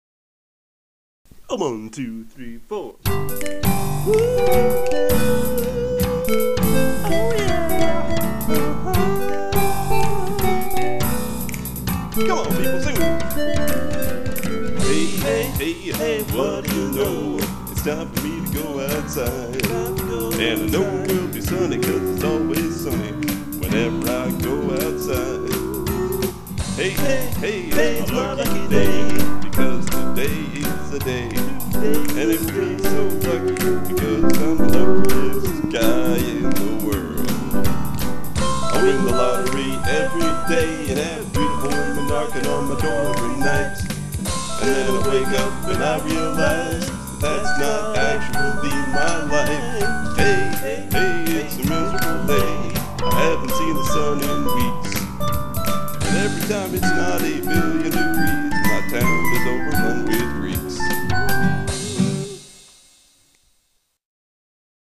Lament in G Minor